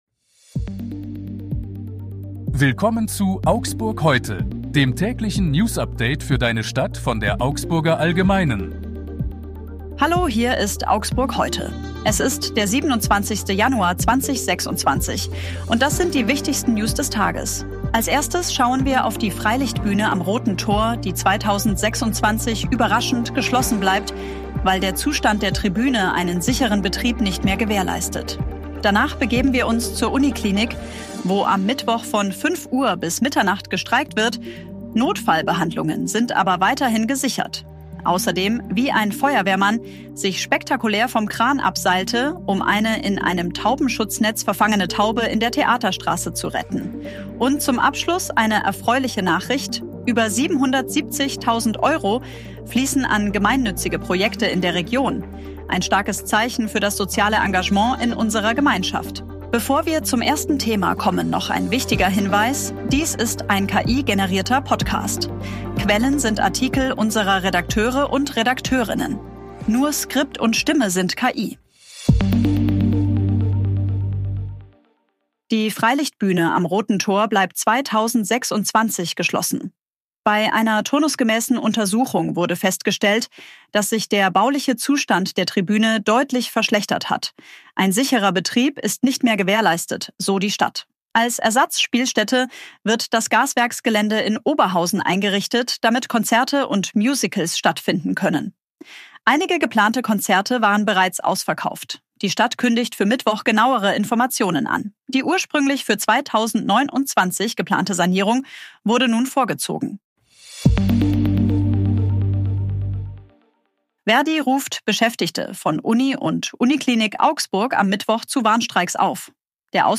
Hier ist das tägliche Newsupdate für deine Stadt.
Skript und Stimme sind KI.